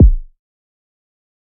GOOD AM Kick.wav